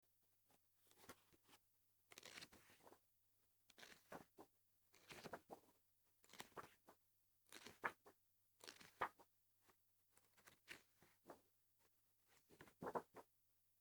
Sound：Book
薄めの本（台本）をまとめて落とす音